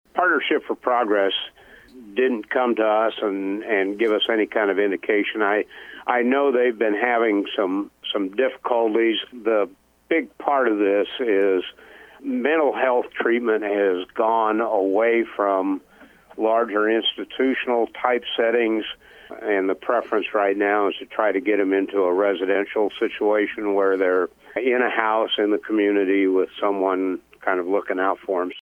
Cass County Board of Supervisors Chair Steve Baier told KJAN the news did not come as a complete surprise to him.